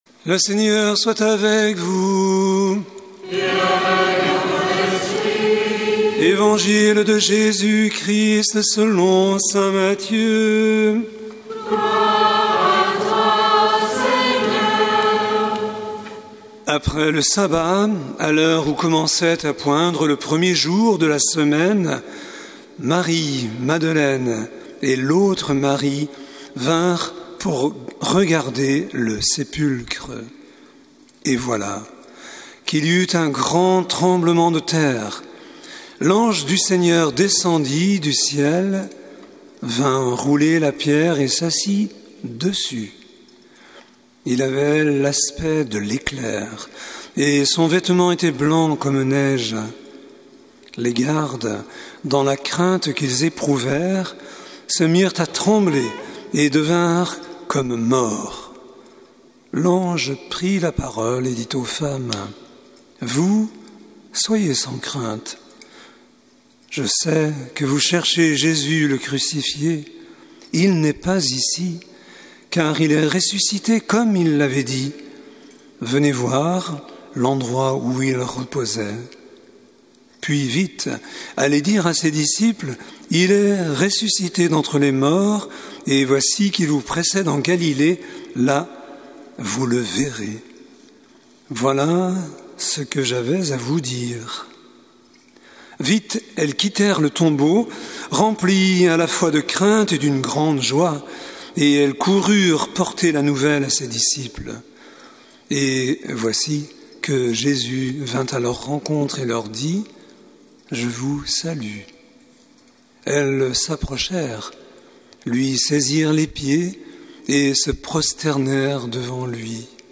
Paroisse St Charles de Foucauld | Samedi Saint 2026
Évangile de Jésus Christ selon saint Matthieu avec l'homélie